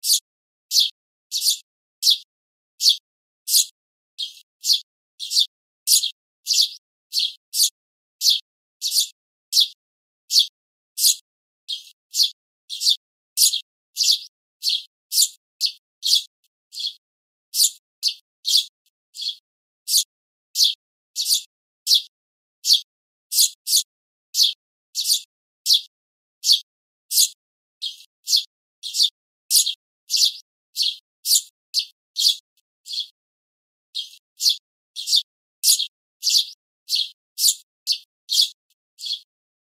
雀（スズメ）の鳴き声 着信音
目覚ましに設定すれば「チュンチュン」とスズメの鳴き声で気持ちよく目覚めれるはず。